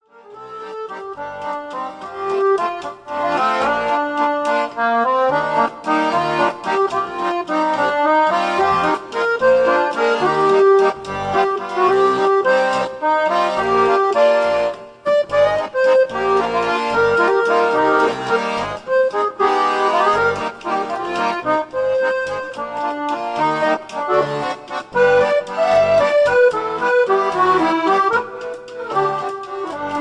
Lilting accordion waltz